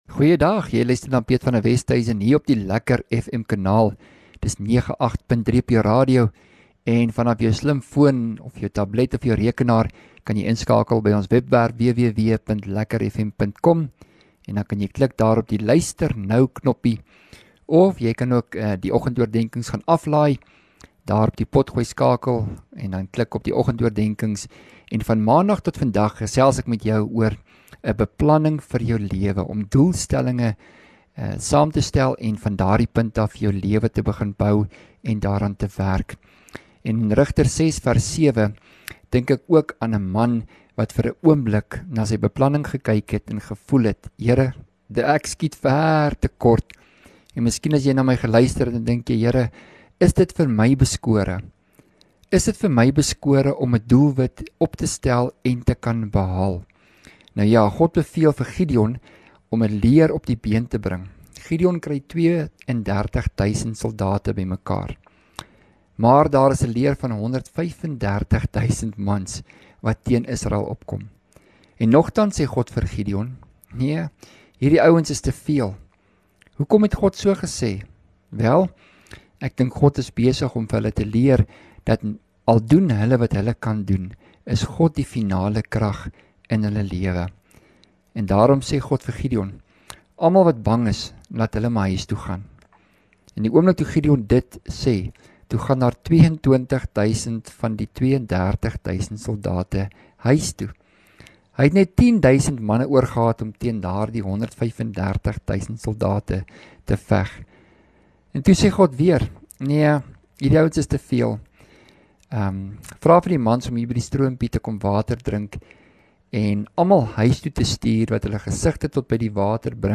Oggendoordenking